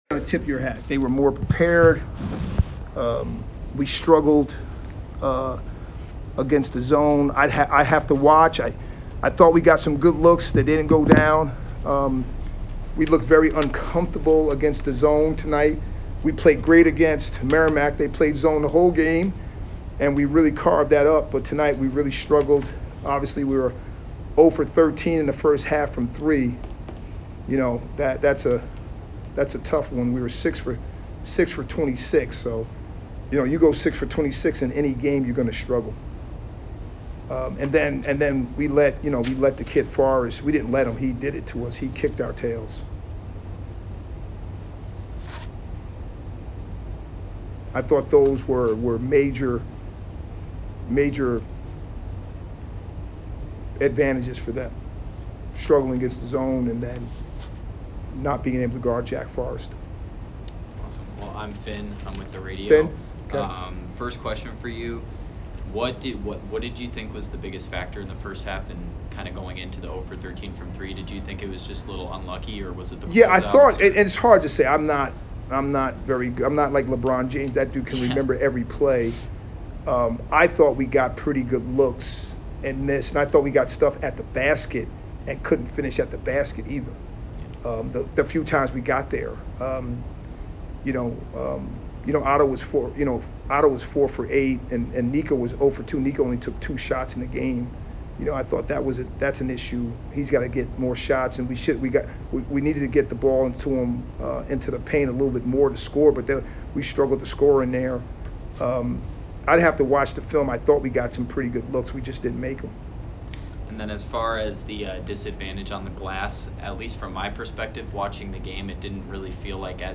Bucknell Postgame Interview